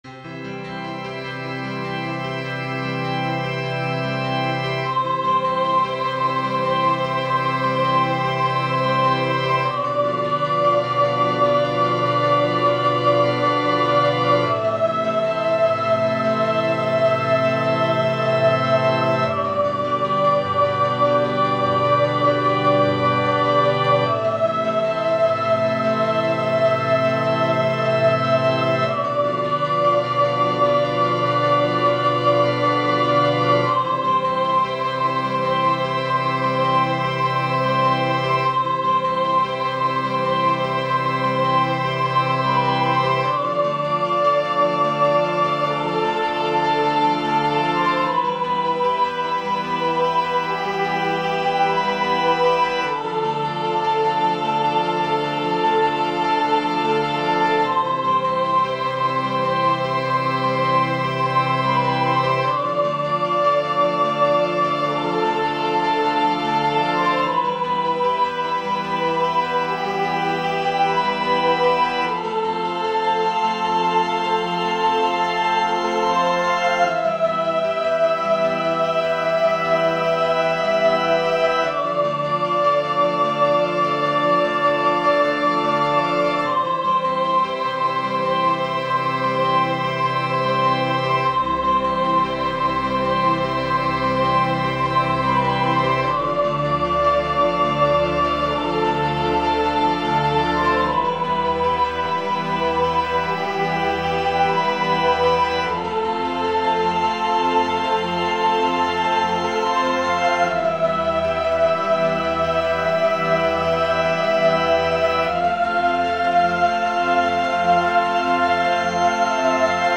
Sacro
Per Soprano e Orchestra
composizione per soprano e orchestra